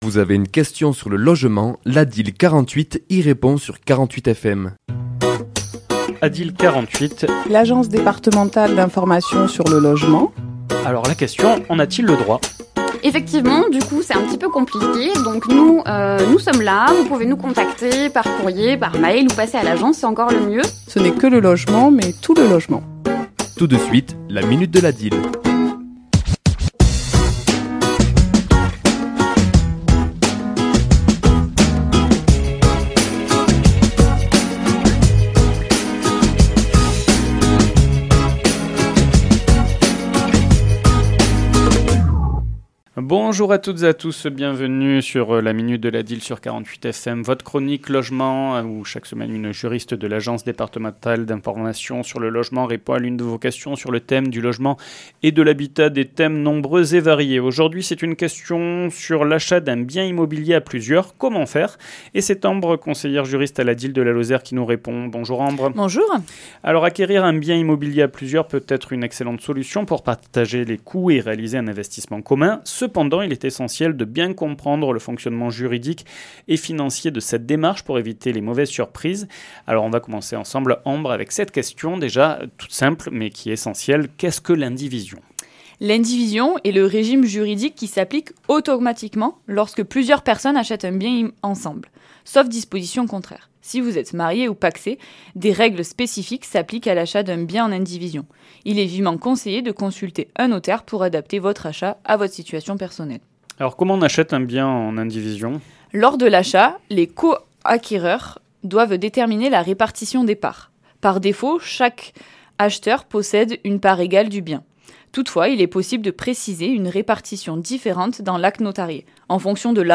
Chronique diffusée le mardi 22 avril à 11h et 17h10